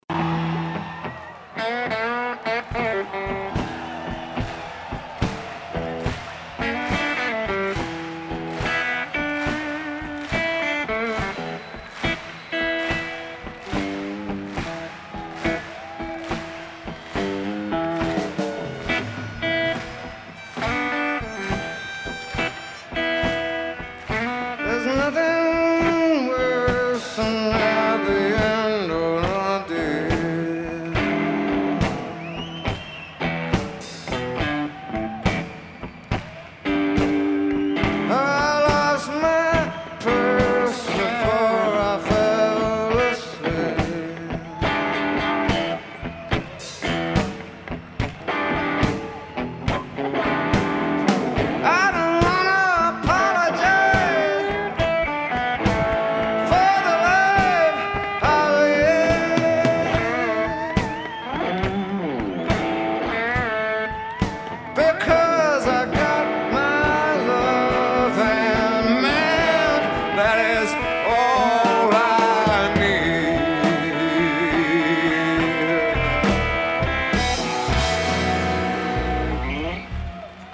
Palau St. Jordi: Barcelona, Spain